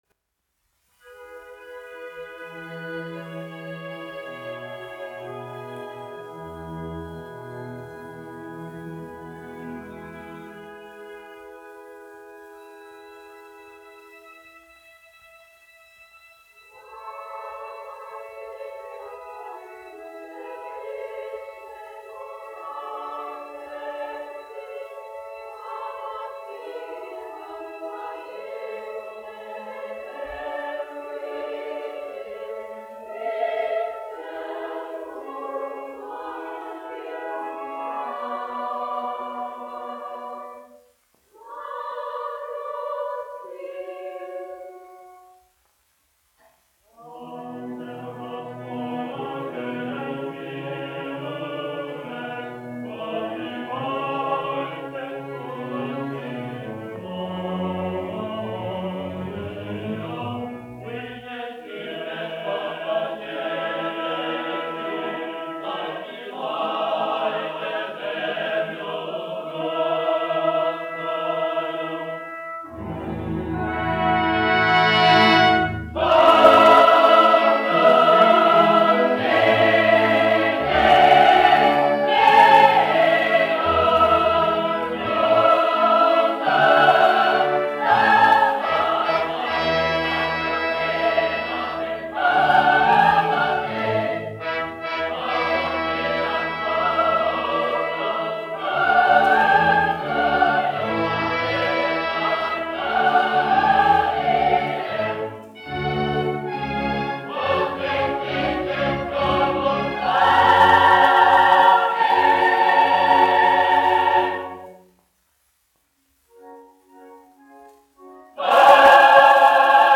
Latvijas Nacionālā opera. Koris, izpildītājs
Emil Cooper, diriģents
1 skpl. : analogs, 78 apgr/min, mono ; 30 cm
Operas--Fragmenti
Latvijas vēsturiskie šellaka skaņuplašu ieraksti (Kolekcija)